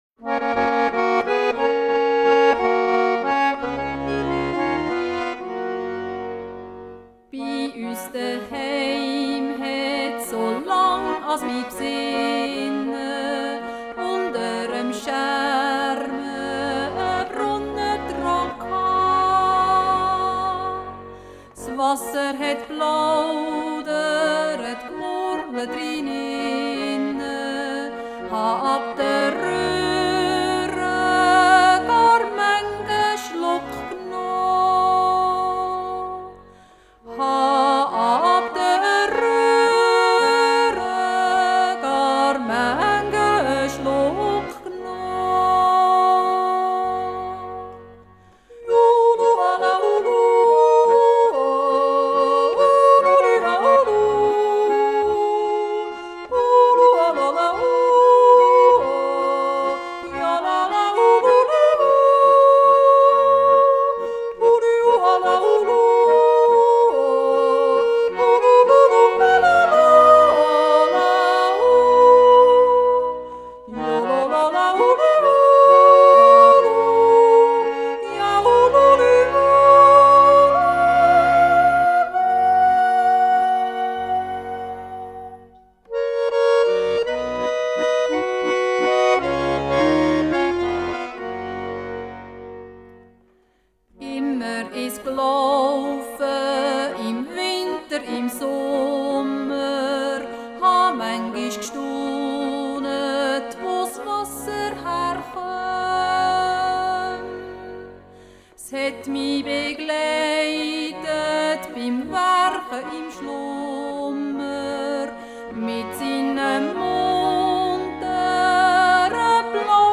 yodel
accordion): De Brunne singt.